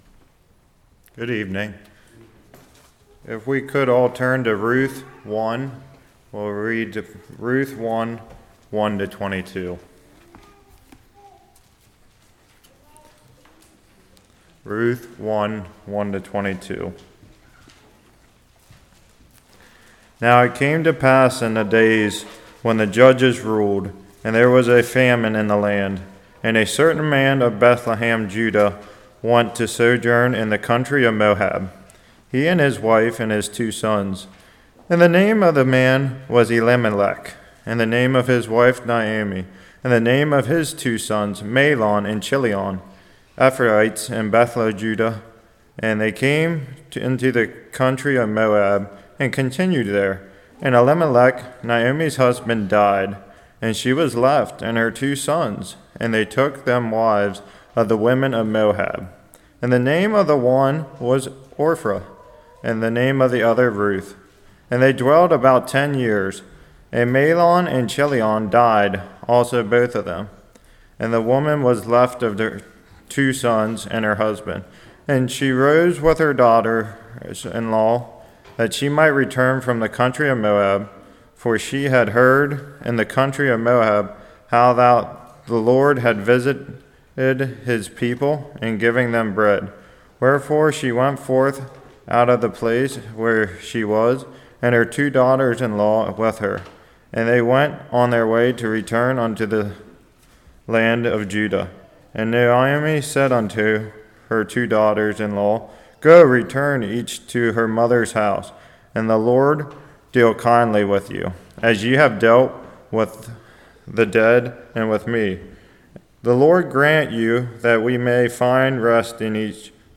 Ruth 1:1-22 Service Type: Evening Why did Elimelech go to Moab?